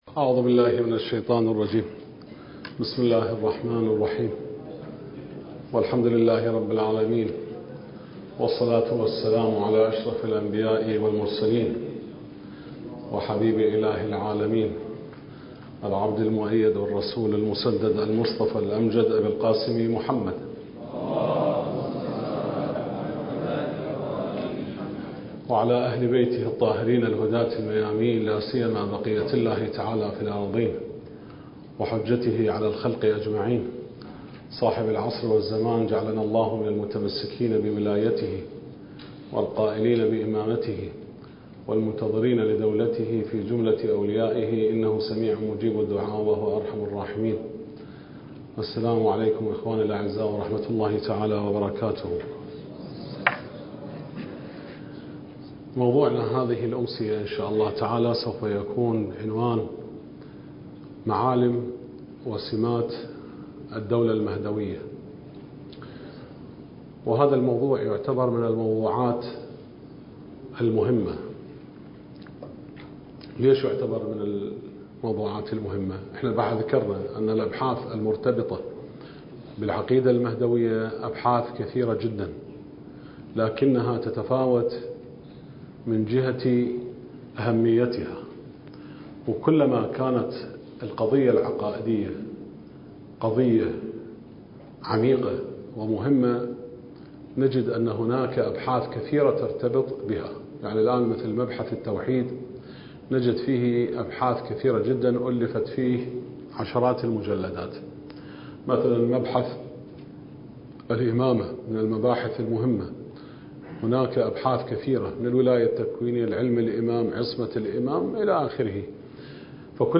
الدورة المهدوية الأولى المكثفة (المحاضرة الخامسة)
المكان: النجف الأشرف